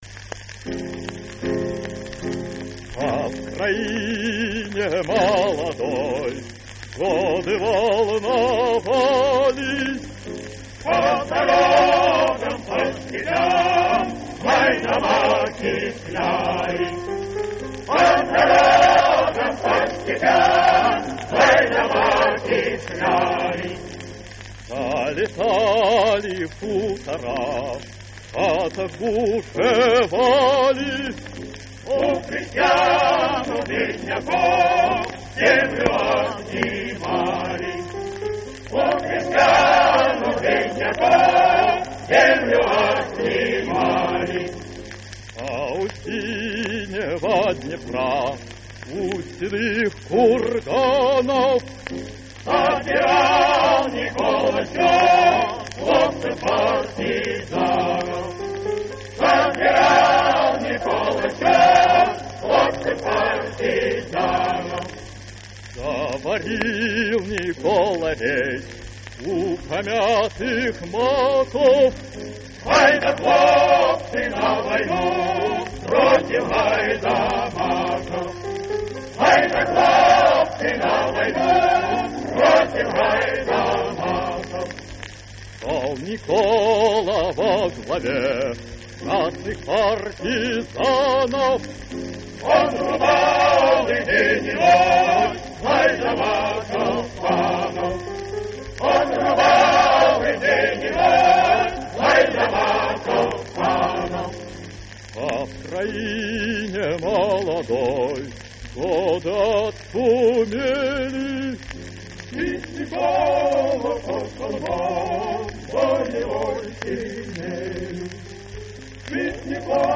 Редкая запись!
ф-но) Исполнение 1937г.